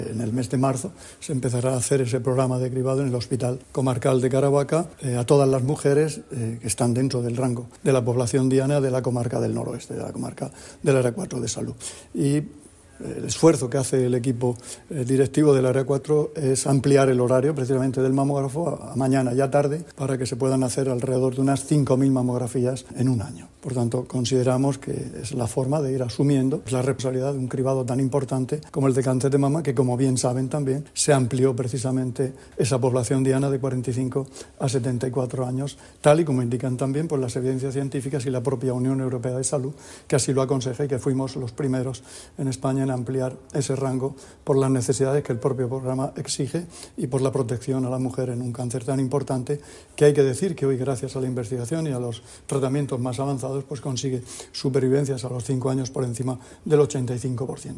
Declaraciones del consejero de Salud, Juan José Pedreño, sobre el cribado de cáncer de mama en el área de salud del Noroeste.